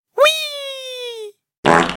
Magical Fart Efeito Sonoro: Soundboard Botão
Magical Fart Botão de Som